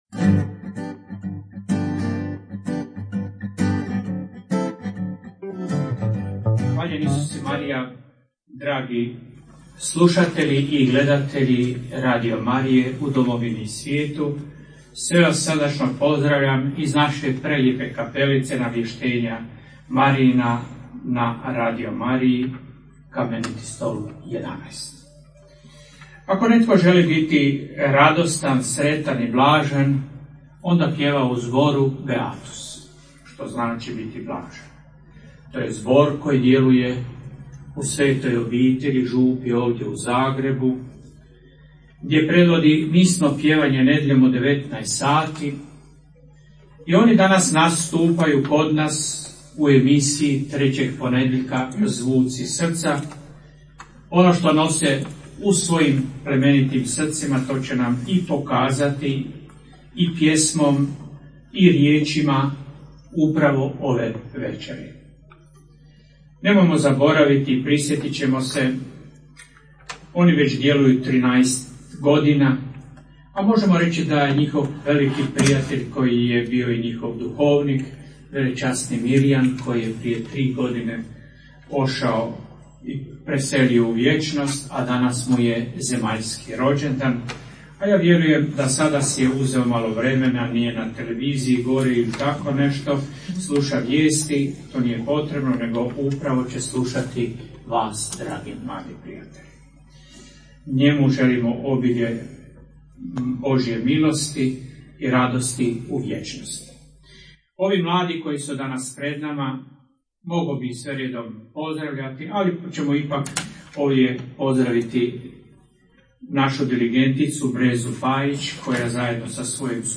Radio Marija Hrvatska - ZVUCI SRCA - koncert zbora „Beatus“ koji djeluje u župi Svete Obitelji u Zagrebu - uživo iz kapelice Radio Marije